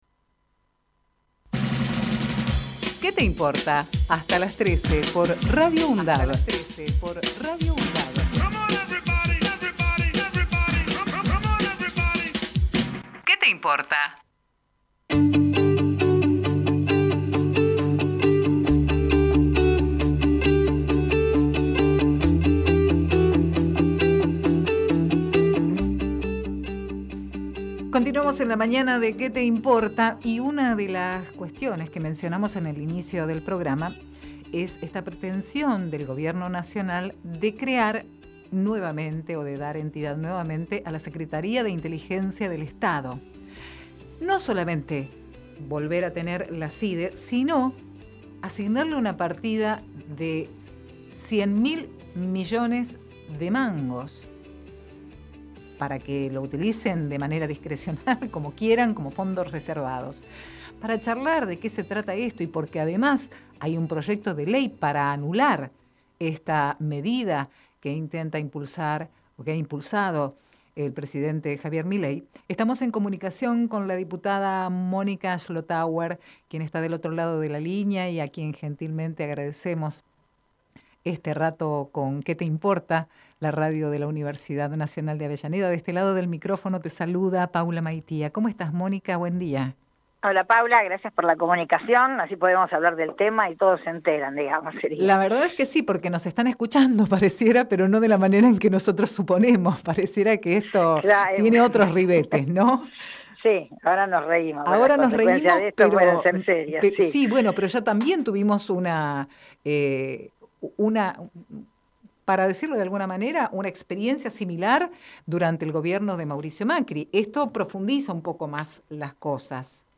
Compartimos entrevista realizada en "Qué te Importa" a la Diputada Mónica Schlothauer. quien presento un proyecto de ley con el fin de anular los decretos de Milei para la creación de la SIDE